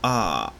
ɑ-02-Open_central_unrounded_vowel.ogg.mp3